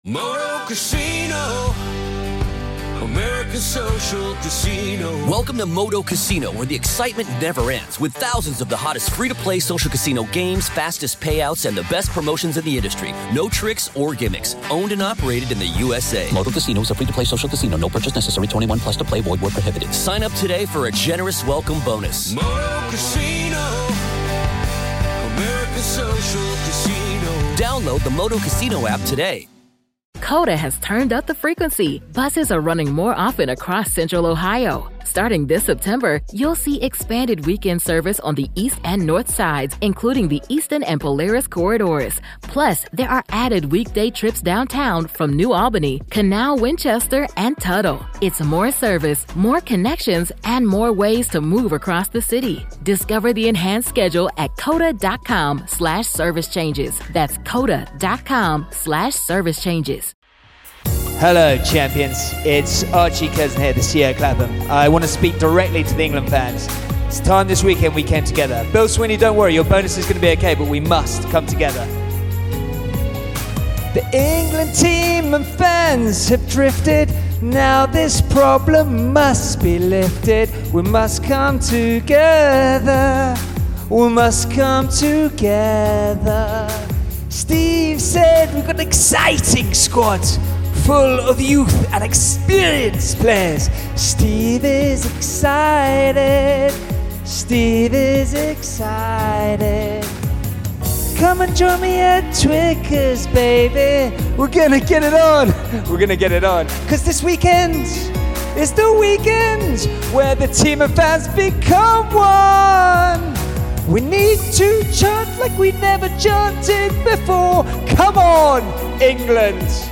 This is a jam packed interview full of incredible stories from off the field, but also Lawrence opens up and shares his frustrations about this current England team, their chances against Ireland this weekend and the RFU and their continued failure and disappointment.